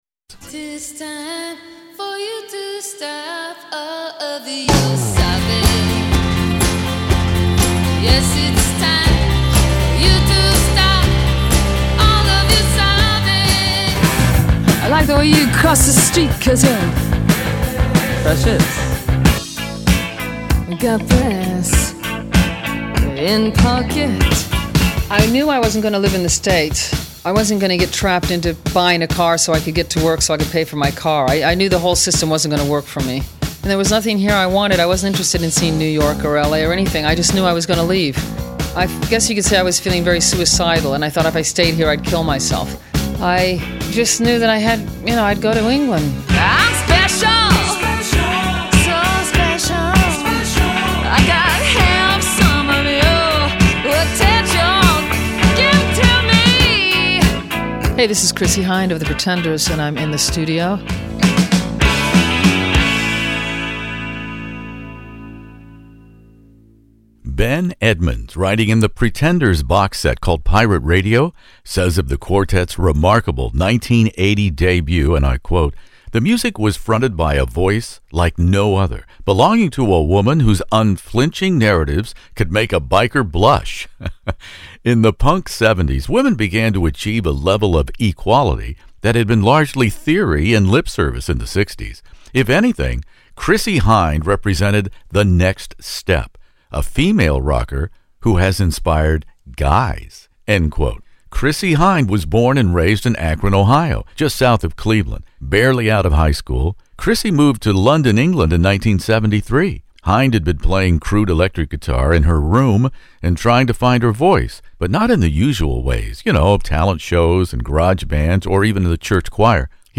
The Pretenders "II" 40th anniversary interview In the Studio with Chrissie Hynde